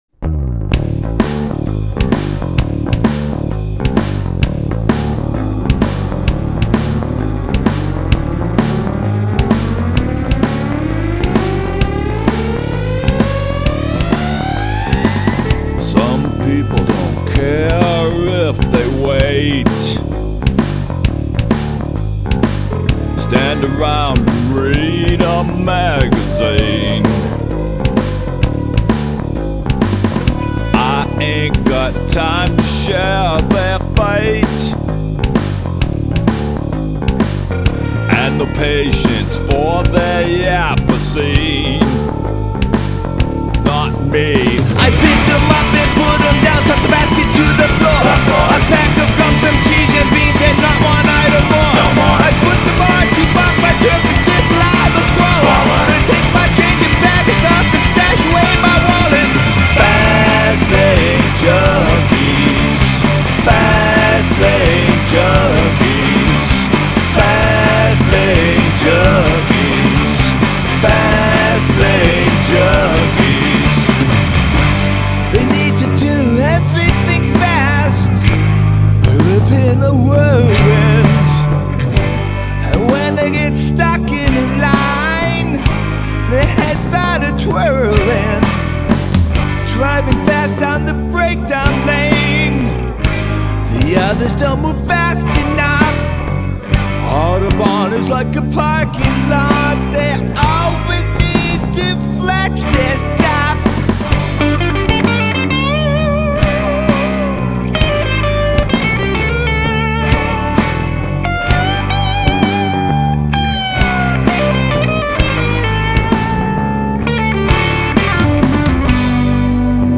I love the bum-ba-dee-dum-dum lumbering bass on this!